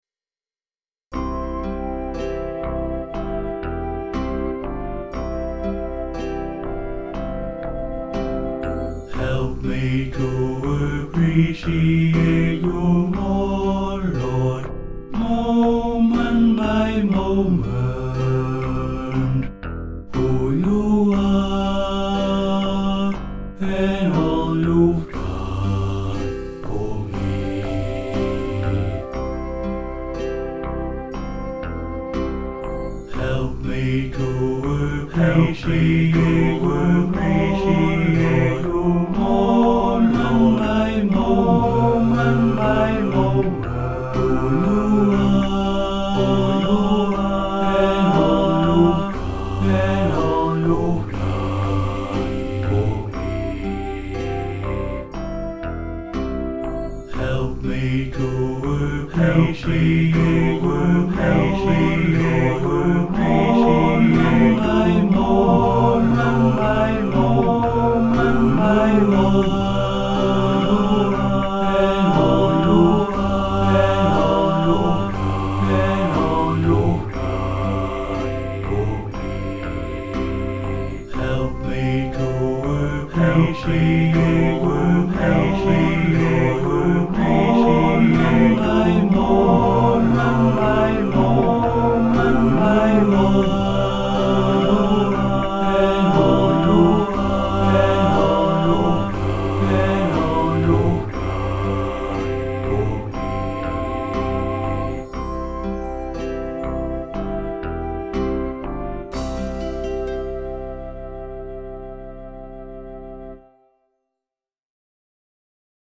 (three part round)